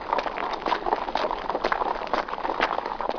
دانلود صدای حیوانات جنگلی 7 از ساعد نیوز با لینک مستقیم و کیفیت بالا
جلوه های صوتی